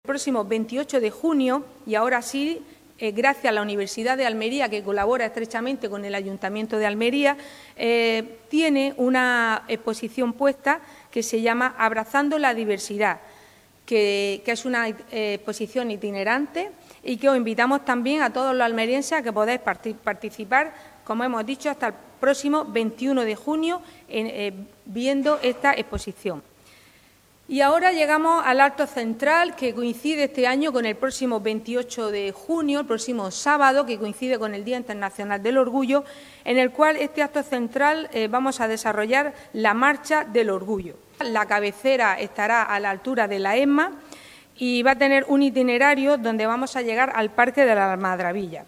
La concejala Paola Laynez presenta este instrumento estratégico de actuación y las actividades con motivo del Día Internacional del Orgullo, arropada por diferentes colectivos y entidades